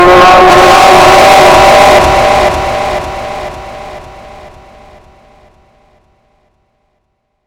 Left To Decay Jumpscare 1 Sound Button - Free Download & Play
Sound Effects Soundboard8 views